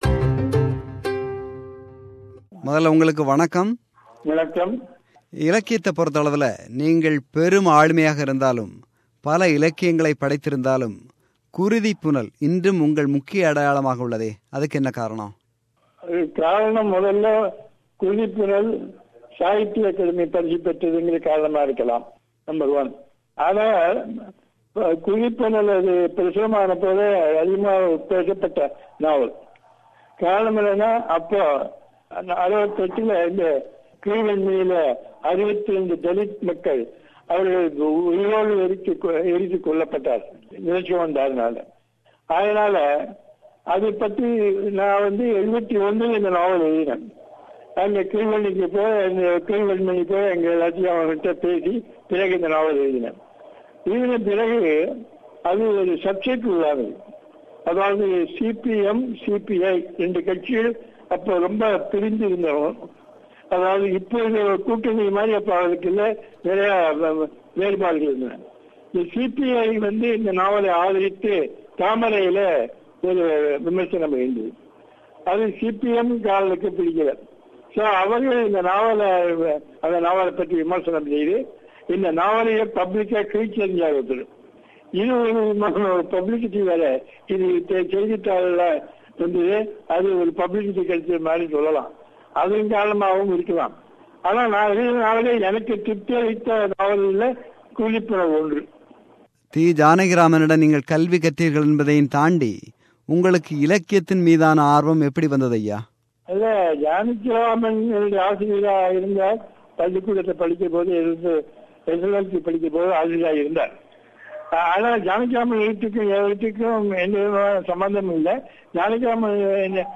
Interview with Indira Parthasarathy – Part 1